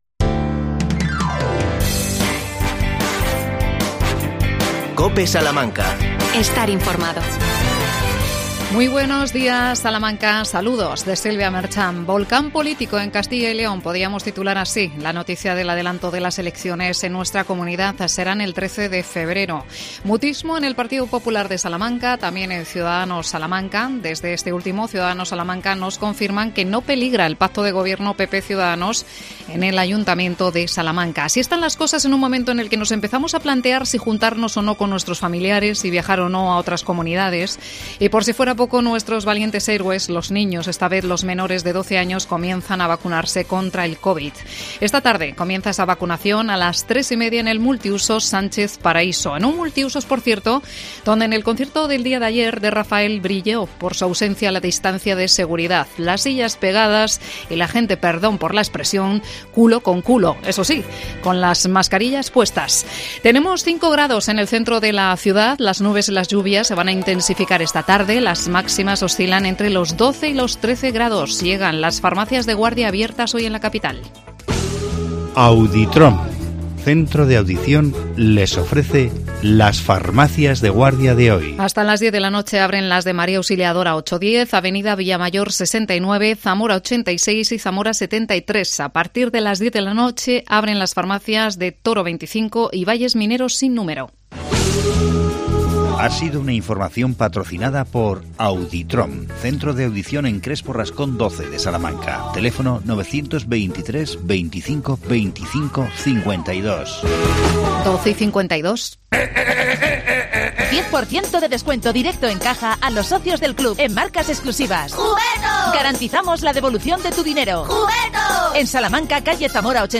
I Campaña de Navidad el Comercio del Centro Histórico de Salamanca. Entrevistamos